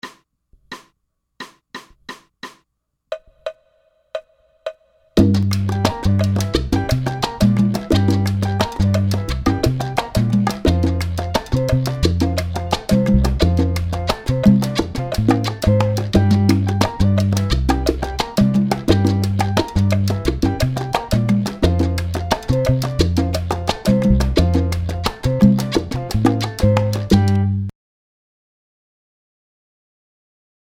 Méthode pour Piano